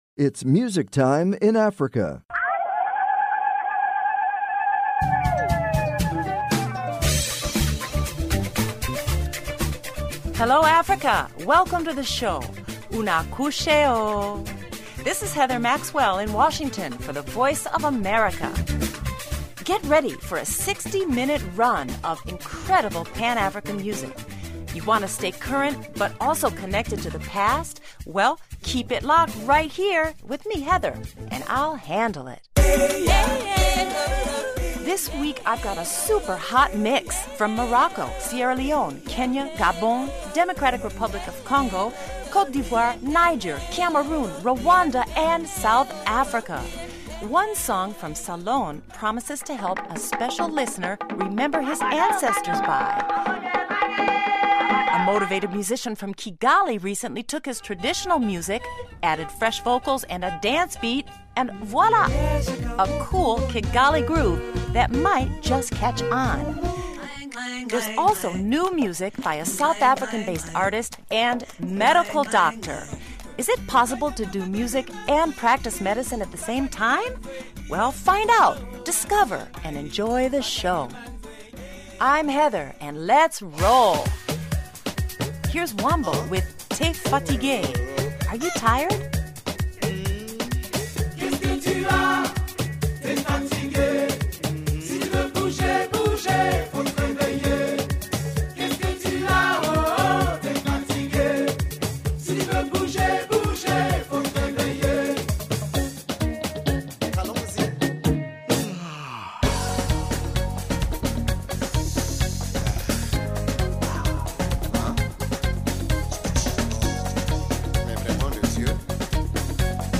exclusive interviews
rare recordings